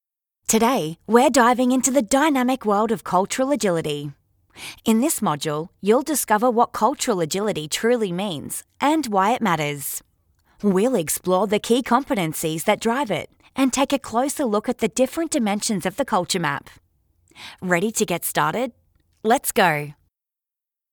Female
Experienced & Versatile Australian Female Voice:
Described as Engaging, Upbeat, Fun, Fancy, Youth, Professional, Sensual, Caring, Motherly, Lively, Cool, Conversational, News Reader, MTV Host, On Hold, Sincere, Gov, Medical, Upbeat =)
E-Learning